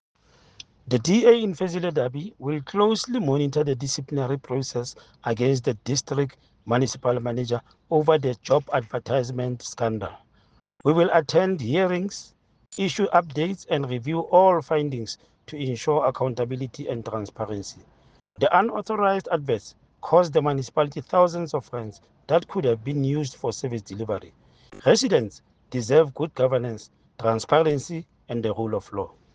Sesotho soundbites by Cllr Stone Makhema and